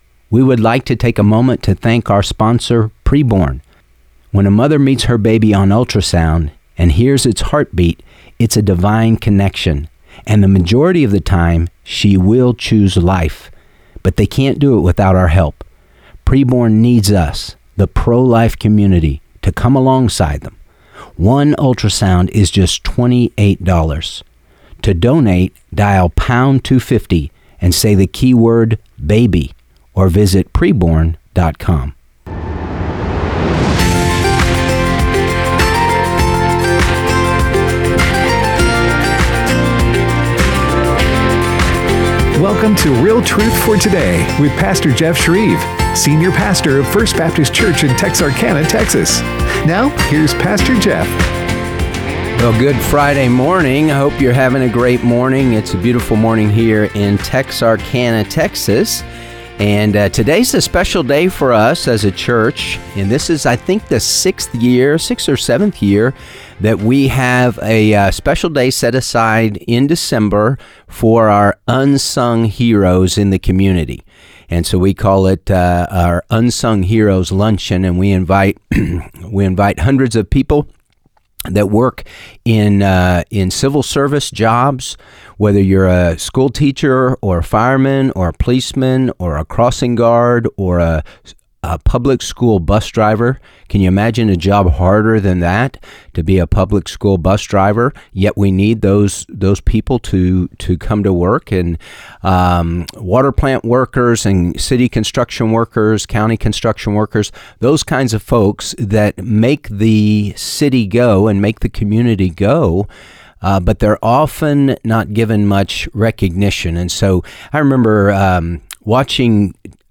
takes questions from listeners.